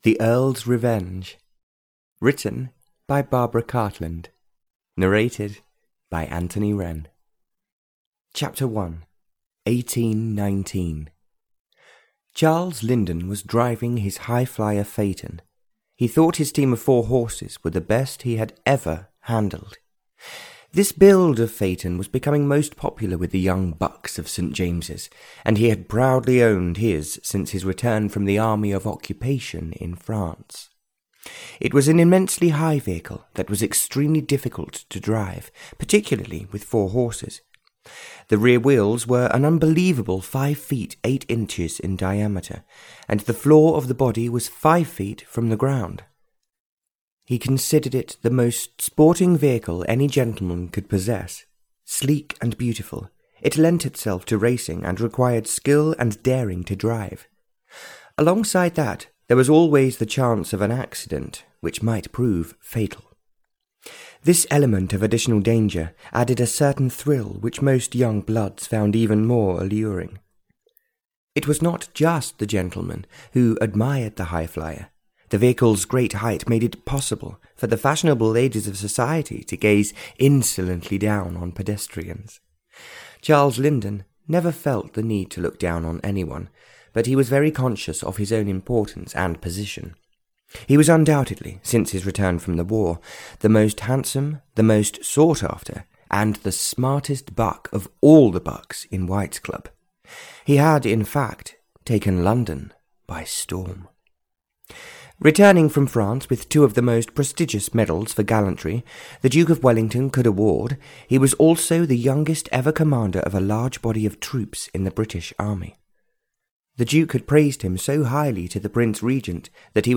The Earl's Revenge (EN) audiokniha
Ukázka z knihy